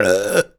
comedy_burp_04.wav